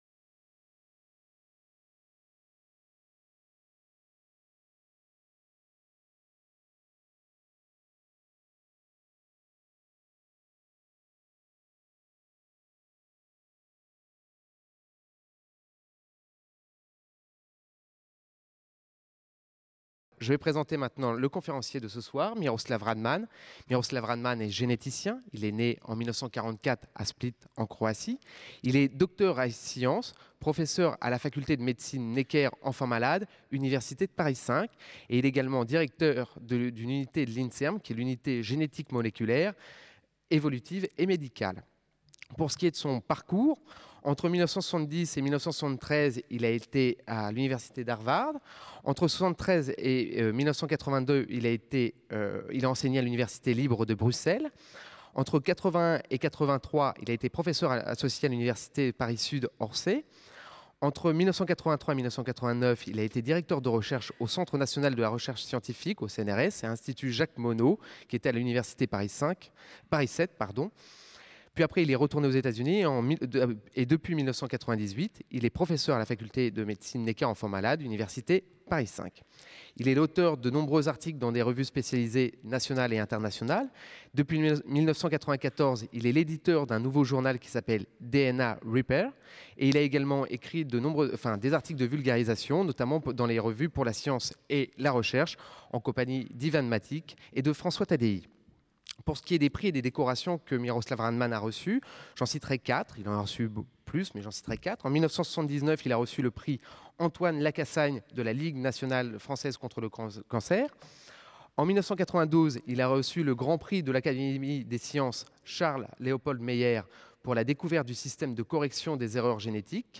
Conférence de l'Université de Tous les Savoirs donnée le 6 juillet 2002 par Miroslav Radman